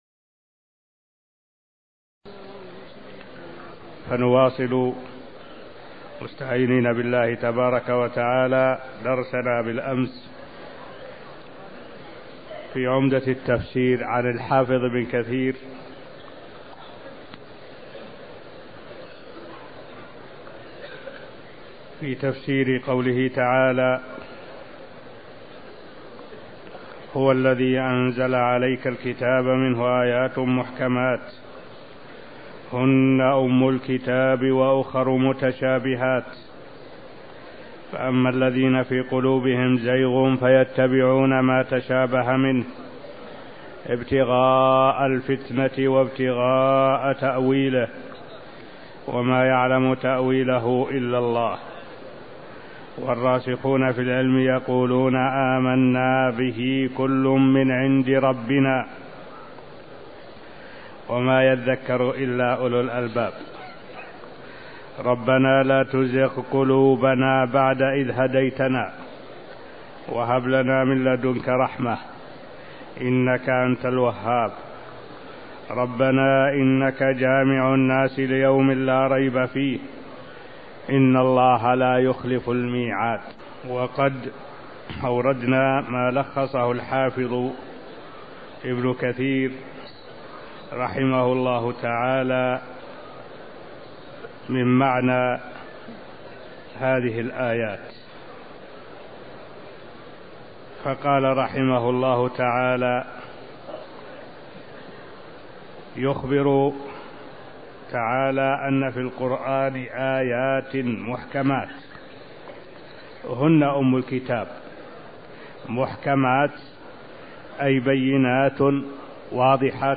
المكان: المسجد النبوي الشيخ: معالي الشيخ الدكتور صالح بن عبد الله العبود معالي الشيخ الدكتور صالح بن عبد الله العبود سورة آل عمران 7-9 (0149)